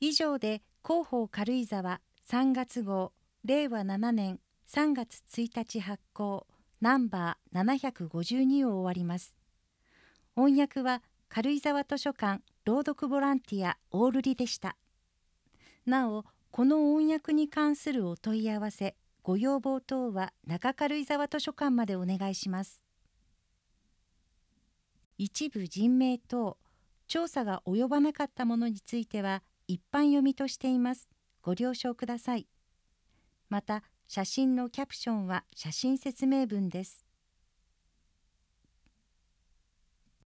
音声データ　軽井沢図書館朗読ボランティア「オオルリ」による朗読